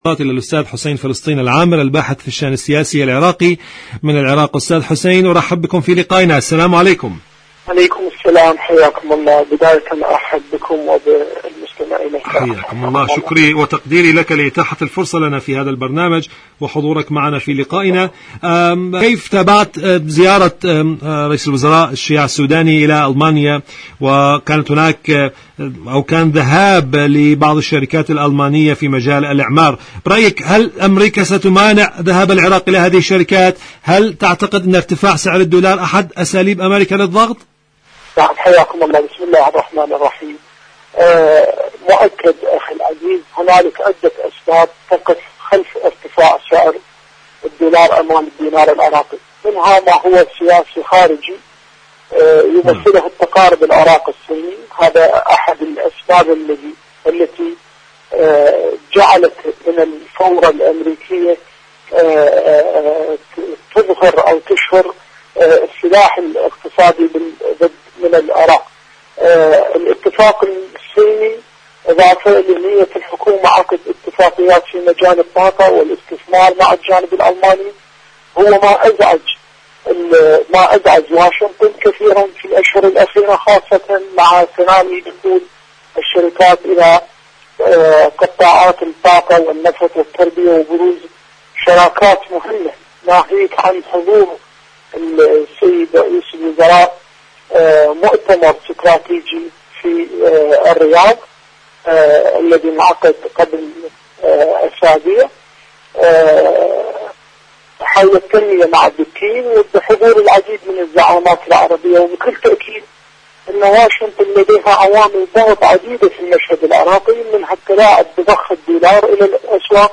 إذاعة طهران-معكم على الهواء: مقابلة إذاعية